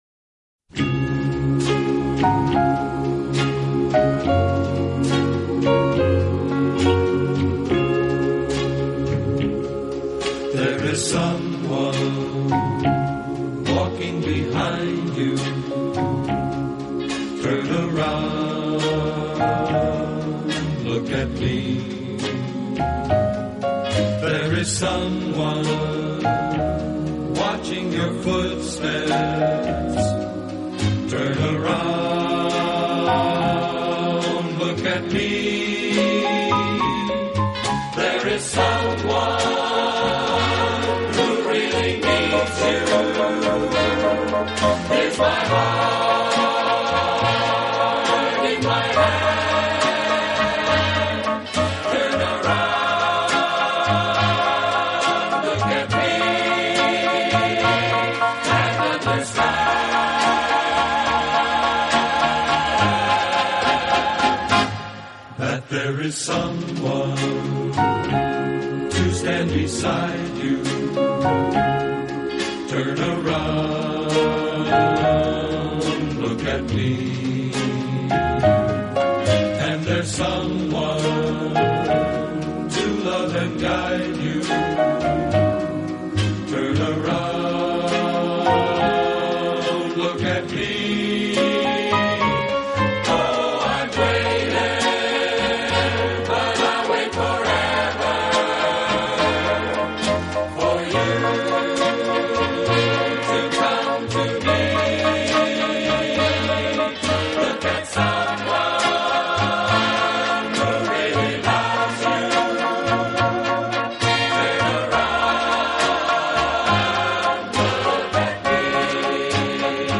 【轻音乐】
他在60年代以男女混声的轻快合唱，配上轻松的乐队伴奏，翻唱了无数热